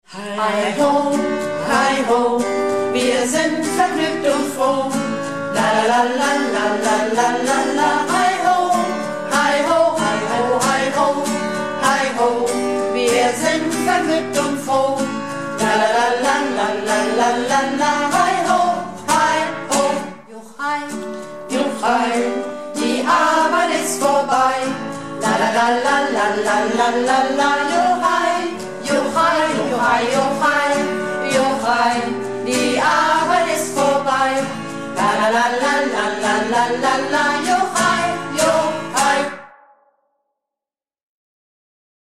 Projektchor "Keine Wahl ist keine Wahl" - Probe am 21.05.19